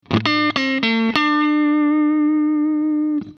In each file I recorded the same phrase with a looper: first with the Original MXR DynaComp inserted at the beginning of the chain and a second recording with the Kemper Stomps. I used HiWatt profiles here, but same results I obtained with Marshall and Fender Amps…
Some Audio tests (first the Original MXR and second the KPA):
DEMO-DYNACOMP-ORIG-3.mp3